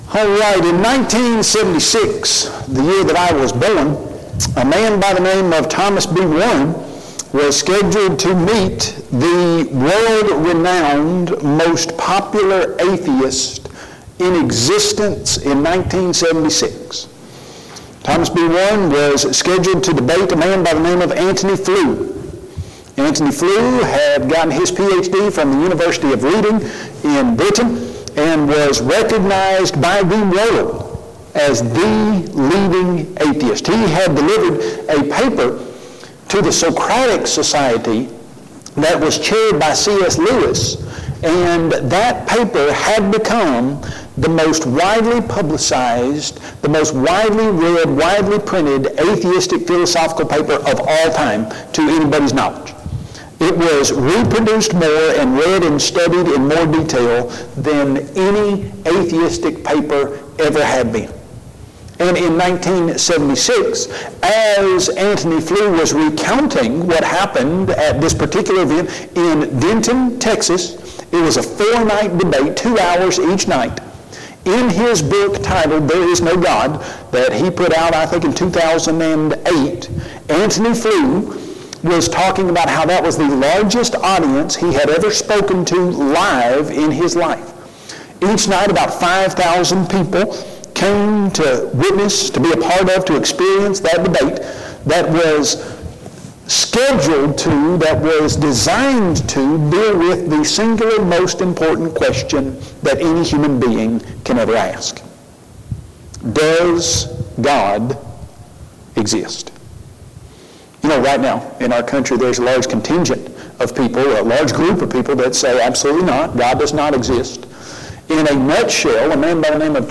Online Christian Apologetics Seminar Service Type: Gospel Meeting